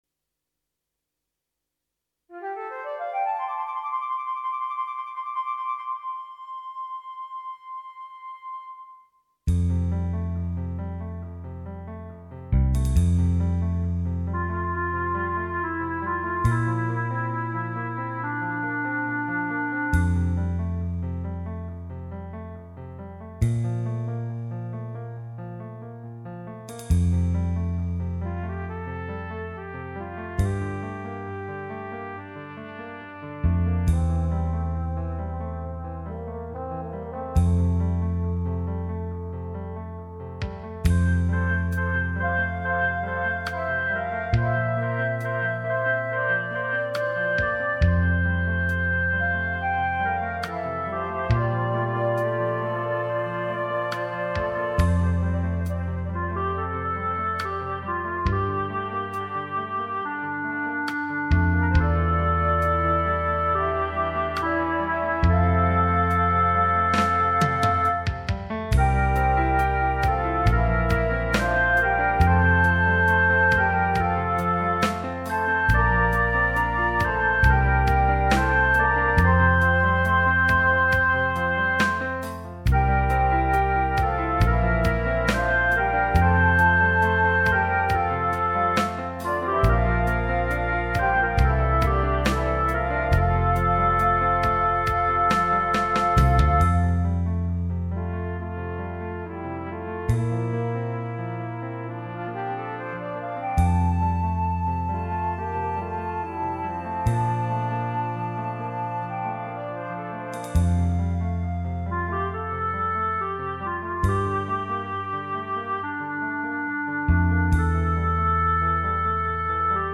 minus Instrument 4